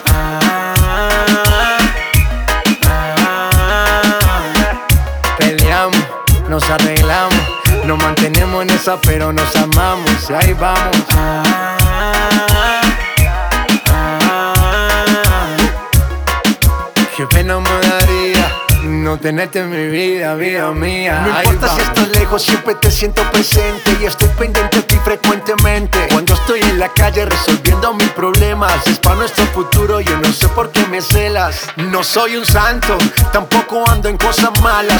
# Latin Urban